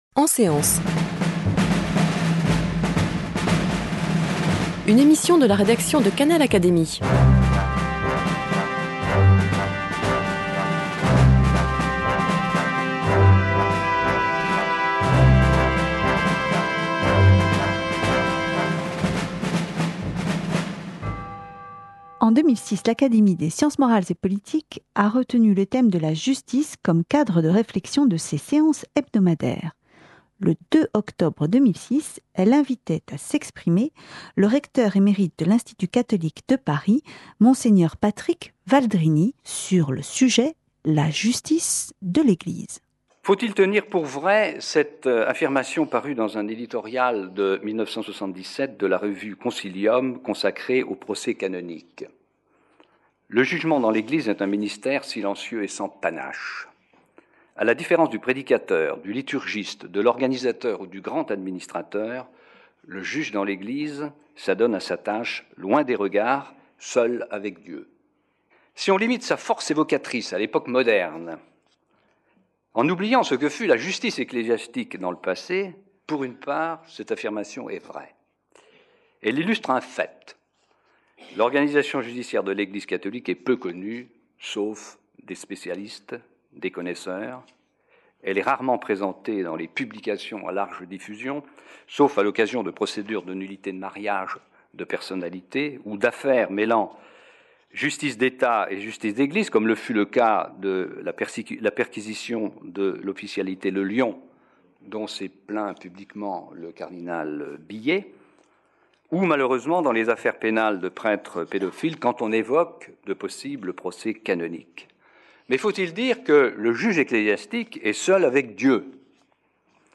prononcée devant l’académie des sciences morales et politiques